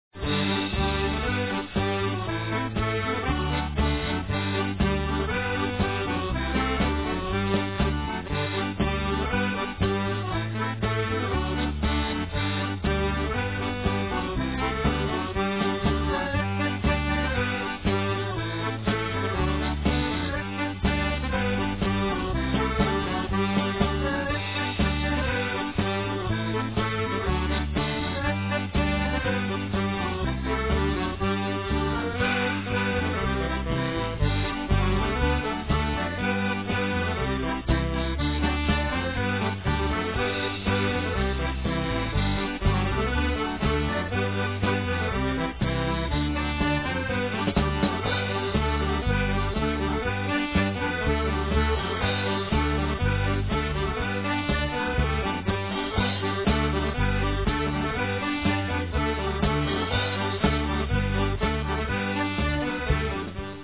7x32 Reels